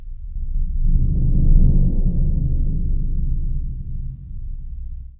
BIG BD 2A -R.wav